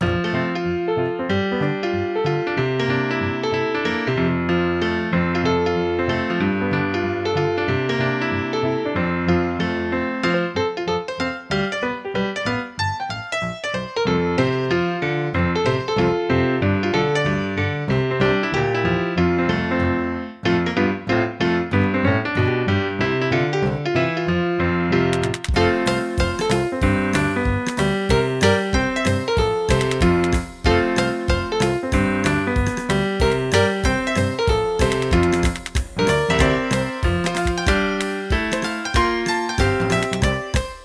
Piano cine mudo (bucle)
piano
melodía
repetitivo
sintetizador